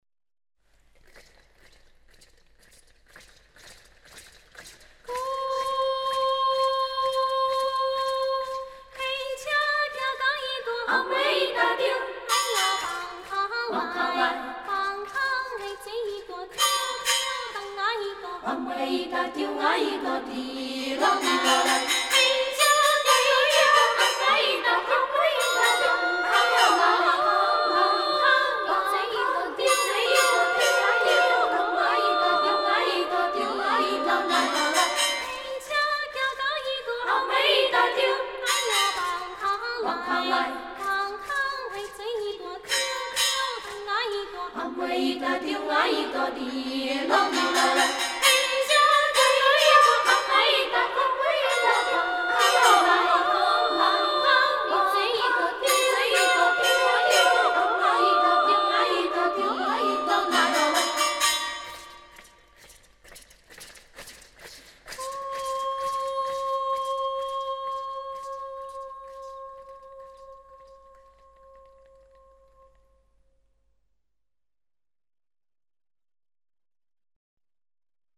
台湾民歌
闽南方言演唱